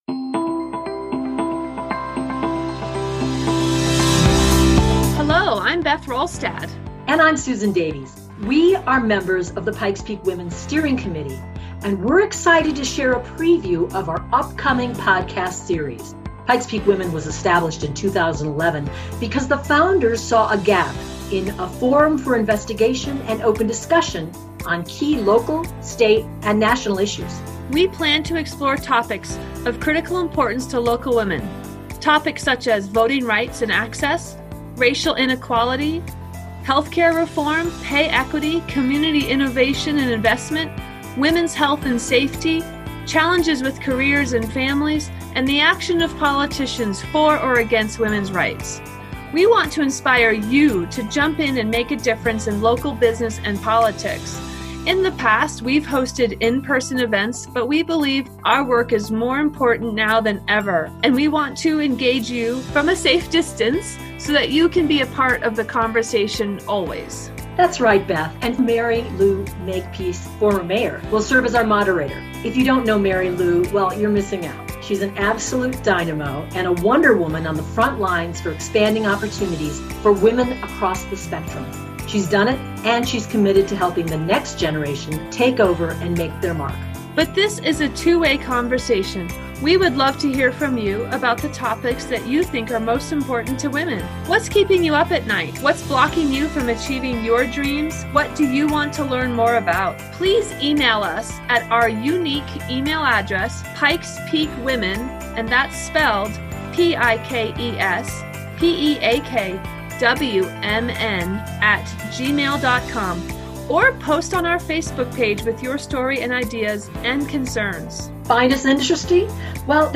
Former Mayor Mary Lou Makepeace will serve as our moderator.
Trailer: